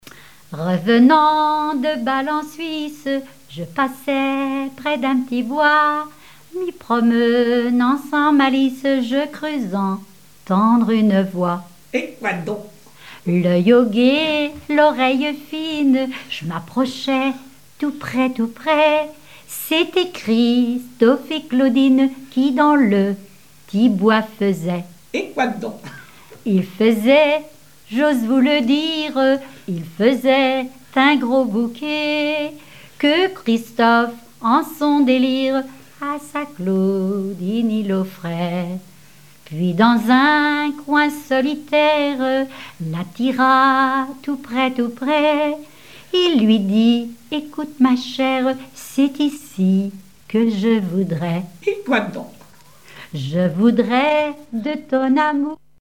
Genre strophique
répertoire de chansons populaires
Pièce musicale inédite